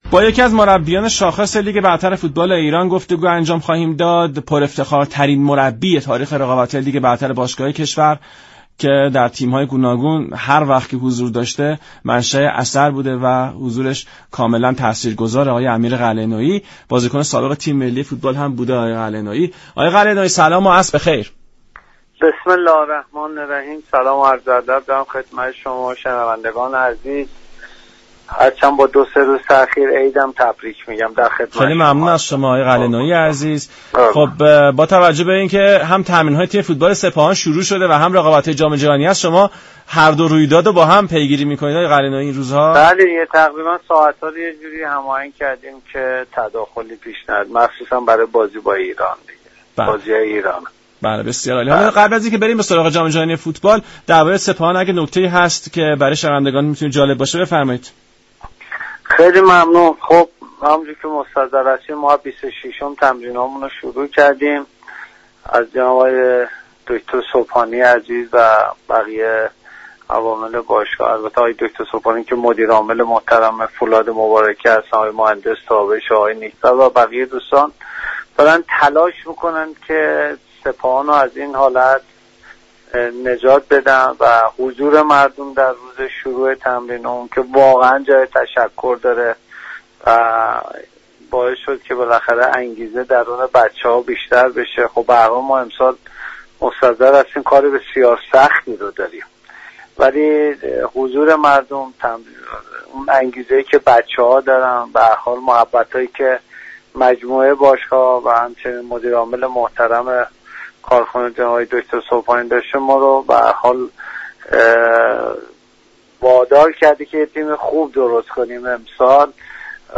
امیر قلعه نویی از مربیان پر سابقه لیگ فوتبال ایران در گفت و گو با «ورزش ایران» گفت: از زمان حضور كارلوس سرمربی ایران در كشور؛ وضعیت تیم ملی شكل منجسم تری به خود گرفته است.